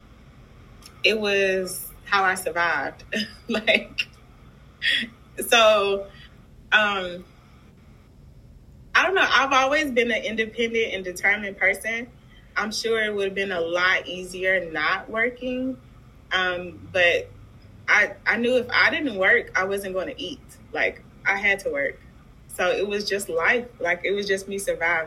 Demographics: Black woman, 38 (Millennial)[22], first-generation college student, raised in single-parent household